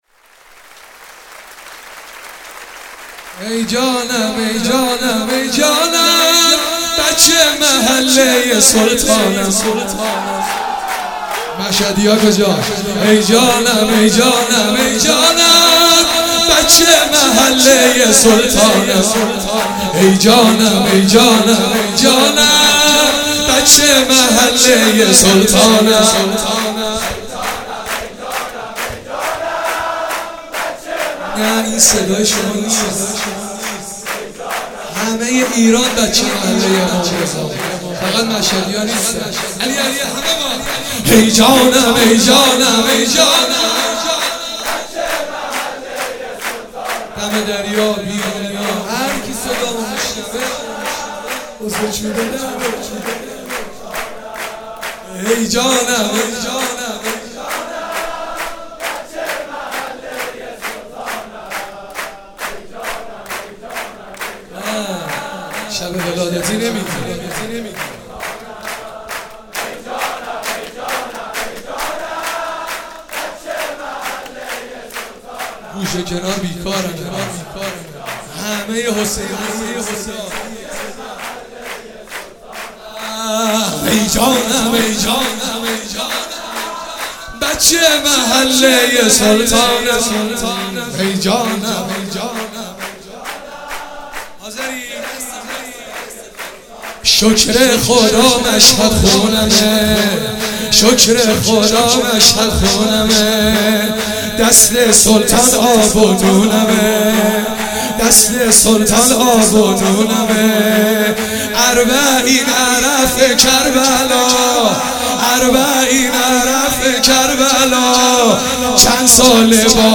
مراسم شب میلاد امام رضا(ع) 96
شور